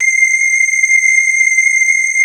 Index of /90_sSampleCDs/Wizoo - Powered Wave/PPG CHOIR